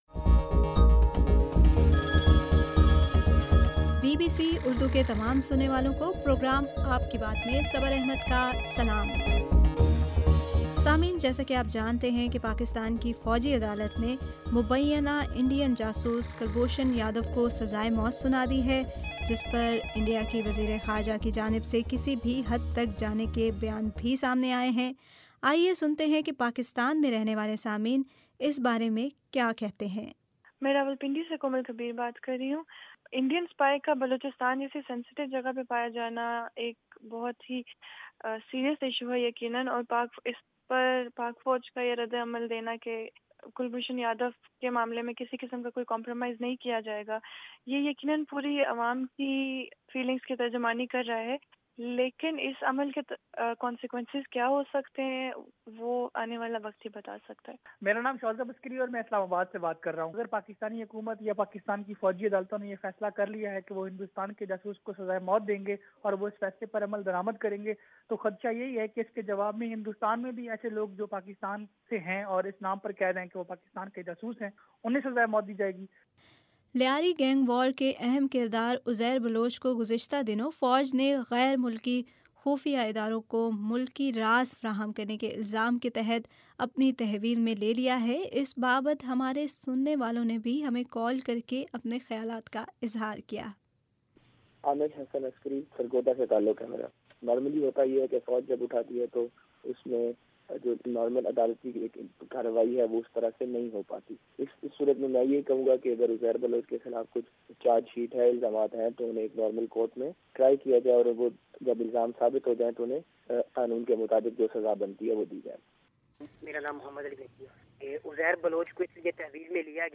آپ کے صوتی پیغامات پر ، مبنی پروگرام ” آپ کی بات ٌ